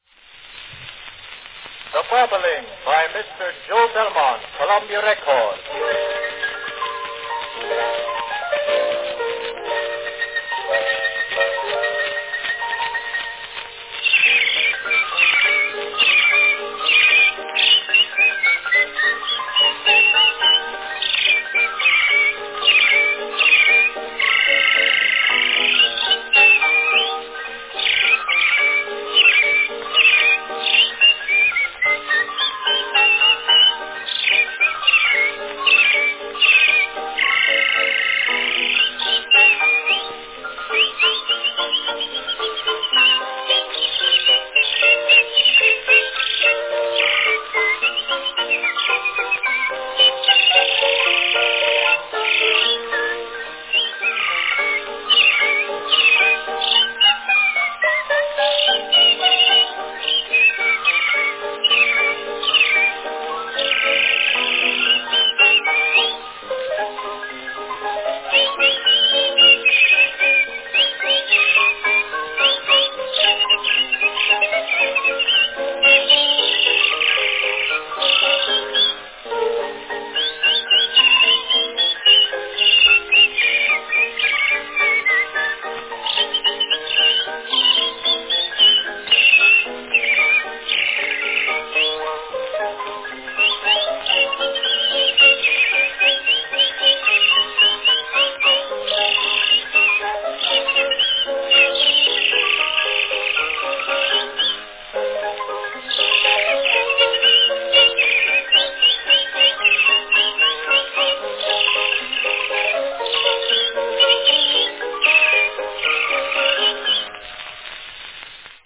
the whistling specialty
Category Whistling